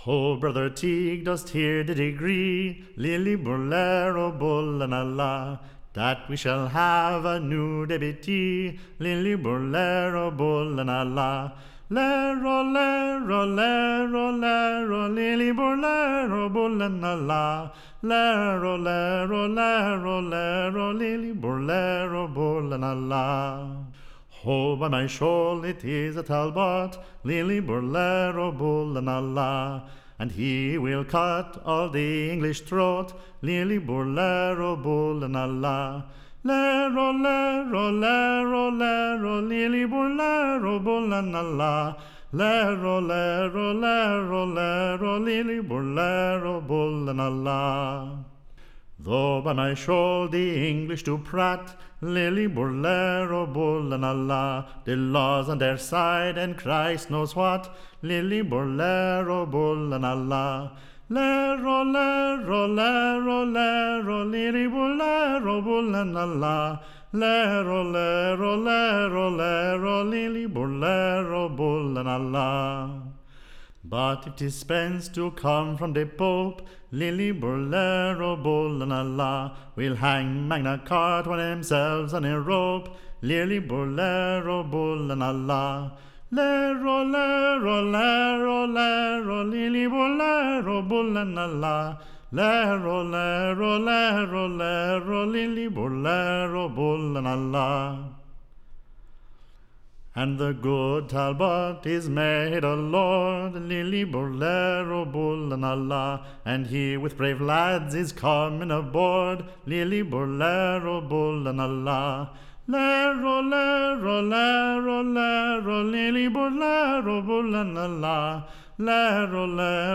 Recording Information Ballad Title A New IRISH Song; / OF / Lil-li bur Lero.